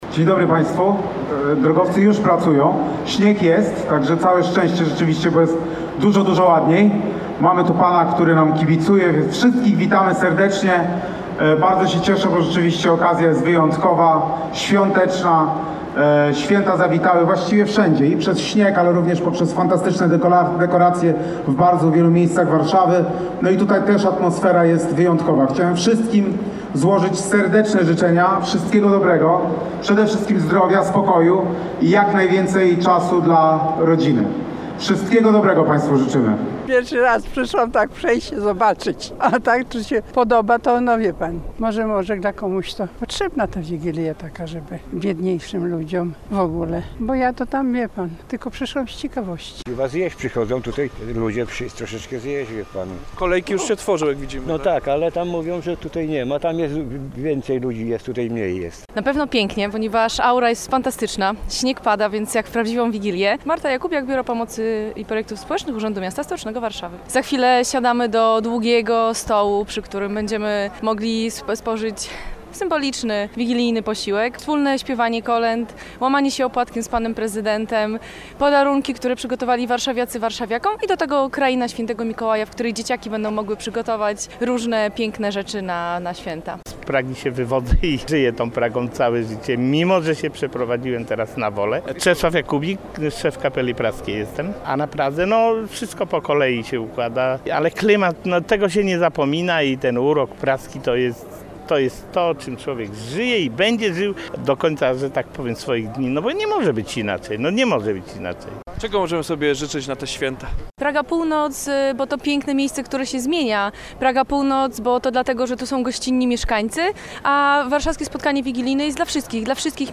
To właśnie tu odbywa się miejska wigilia dla mieszkańców Warszawy. Na stołach pojawiły się tradycyjne potrawy wigilijne oraz małe prezenty dla uczestników wydarzenia.
Na początku życzenia złożył prezydent miasta Rafał Trzaskowski.